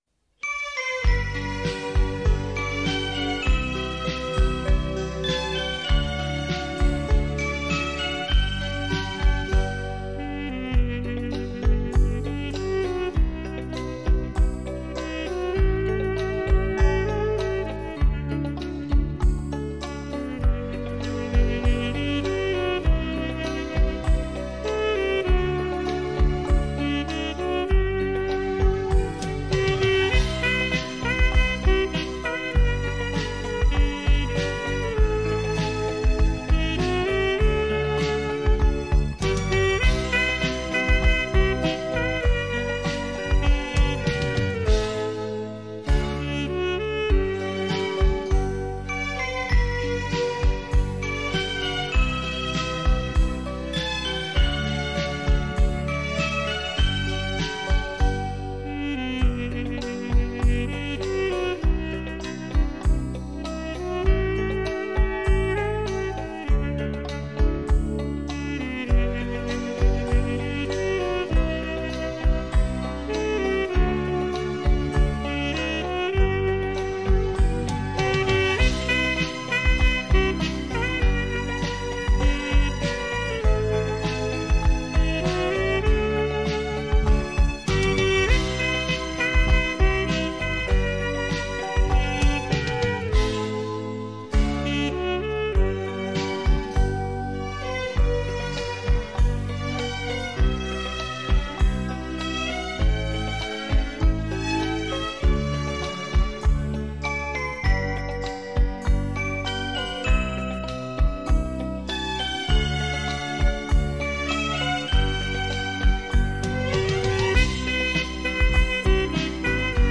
SAXOPHONE TILL GUIT EXORCI
一曲伴以极似人声的萨克斯音乐，
从容悠然的宽松音色令人倍感休闲的意趣与爽快。
柔缓的萨克斯风，让您在萨克斯璀燦宽适的音色与无与伦比的音场音效中享受翩翩起舞的心情。
伦巴